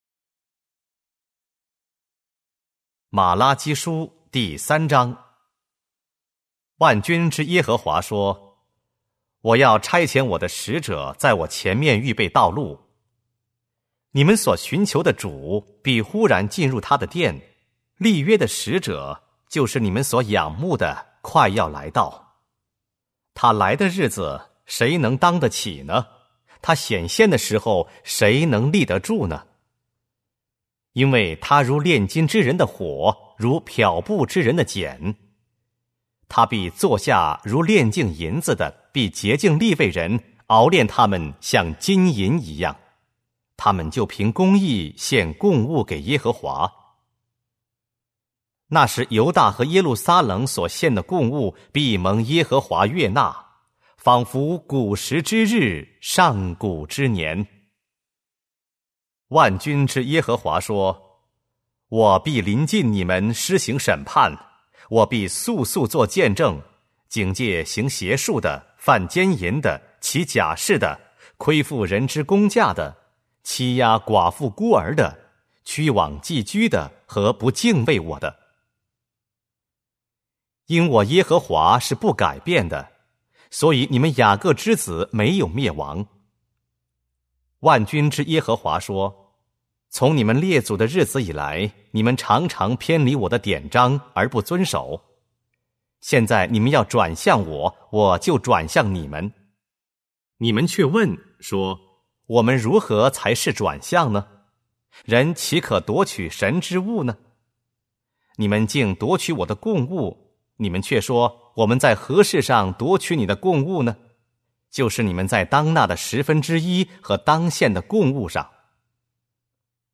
和合本朗读：玛拉基书